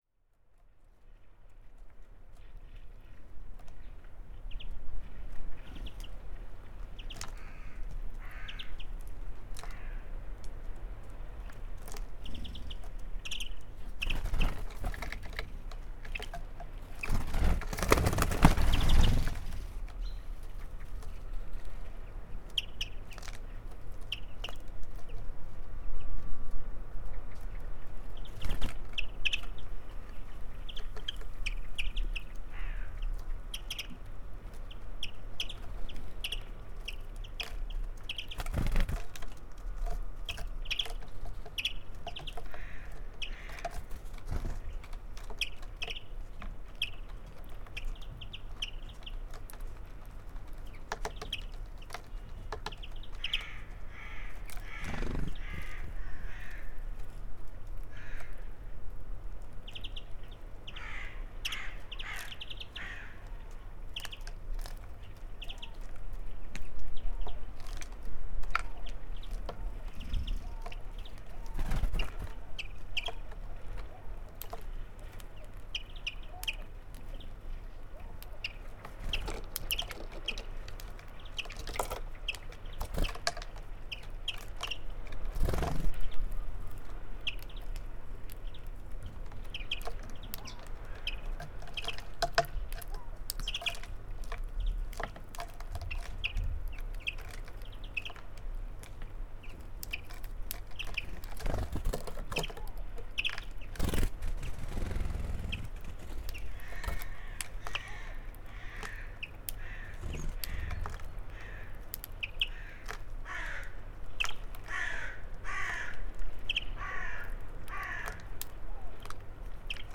101218, European Greenfinch Chloris chloris, Common Chaffinch Fringilla coelebs, calls